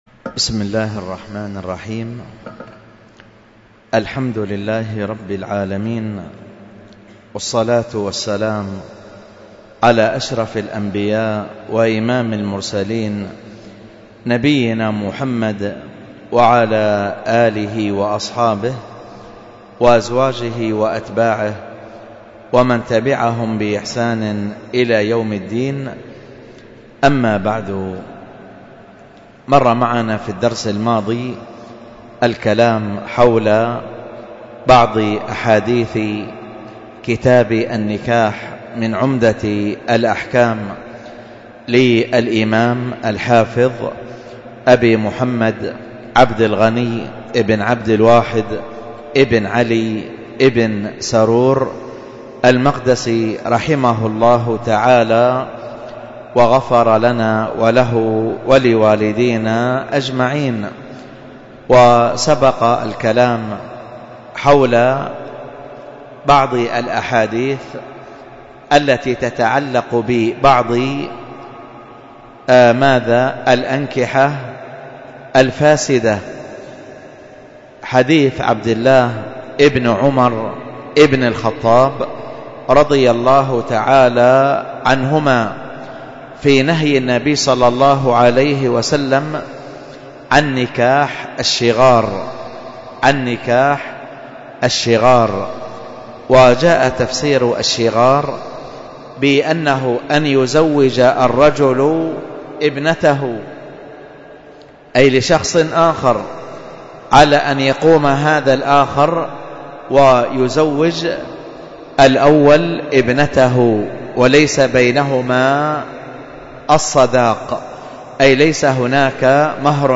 الدرس في التعليقات على كتاب الأدب المفرد 261، ألقاها